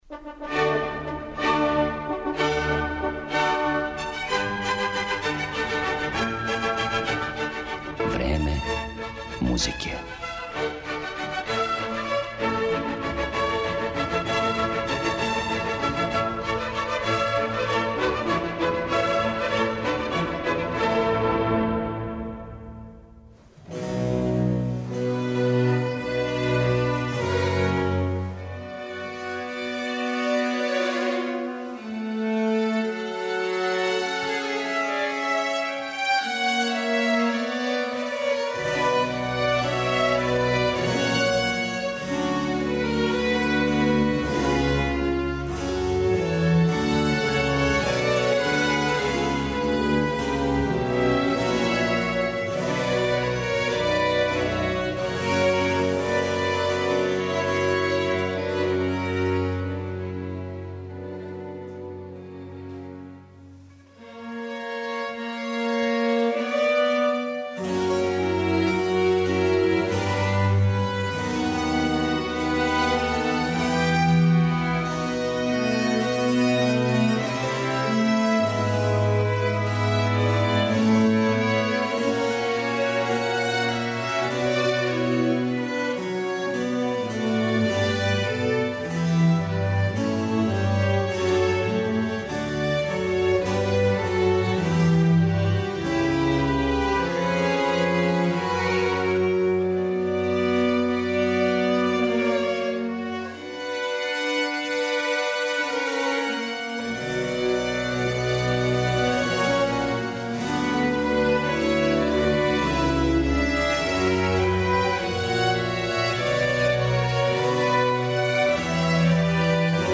чији чланови свирају на оригиналним старим инструментима